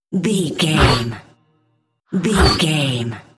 Sci fi whoosh fast
Sound Effects
Fast
futuristic
whoosh